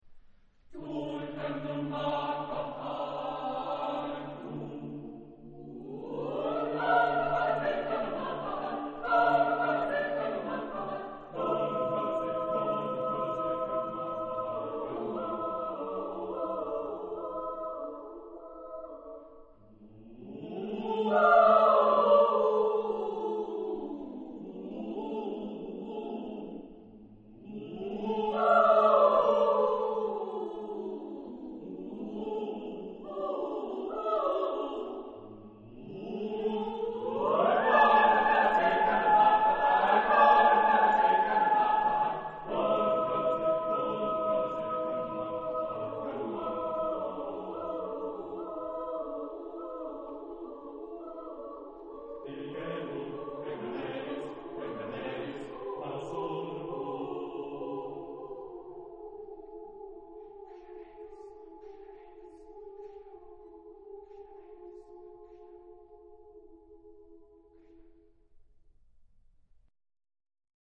Genre-Style-Forme : contemporain ; Chanson ; Profane
Type de choeur : SSATB  (5 voix mixtes )
Tonalité : ré mineur